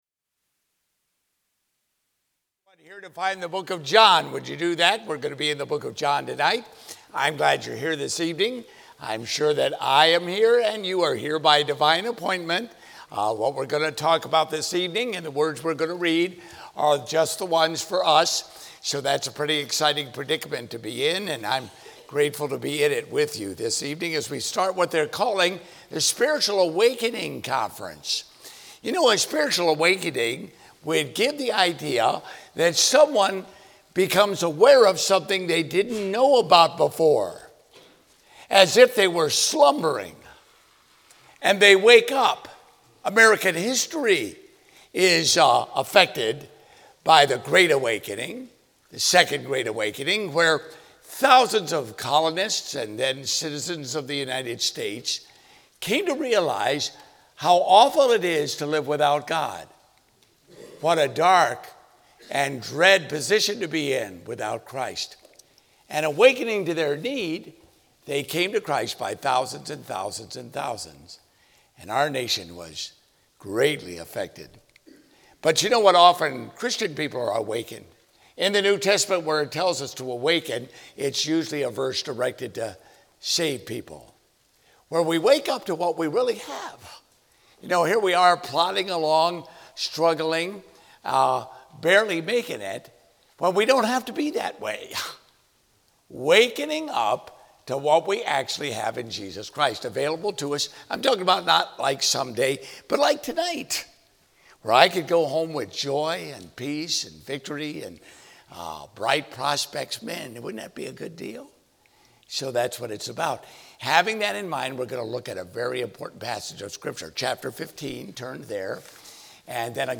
Spiritual Awakening Conference 2019 Archives - Page 2 of 2 - Falls Baptist Church